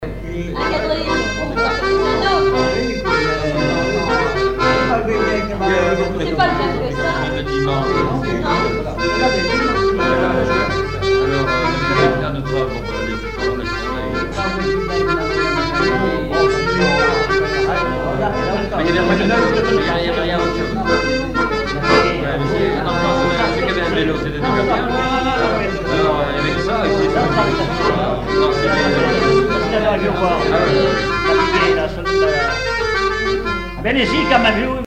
Mémoires et Patrimoines vivants - RaddO est une base de données d'archives iconographiques et sonores.
Polka
Chants brefs - A danser
danse : polka piquée
chansons et instrumentaux
Pièce musicale inédite